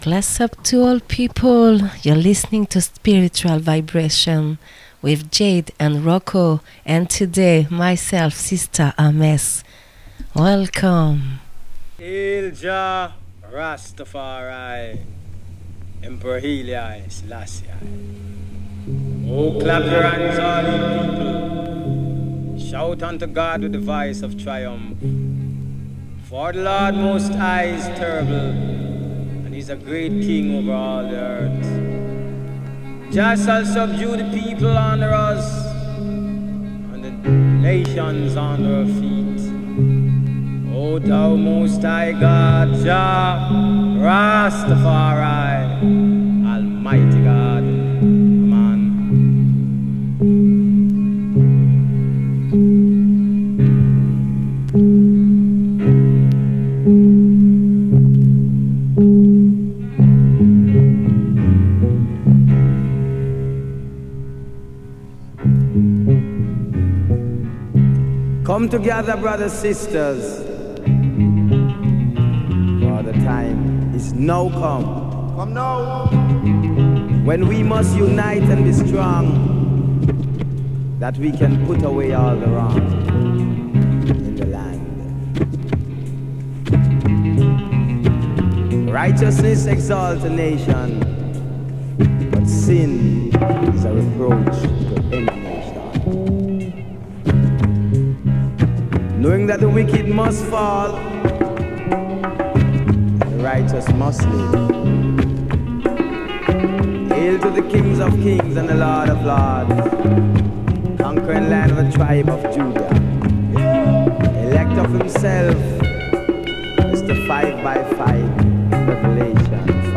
playing Strictly Vinyl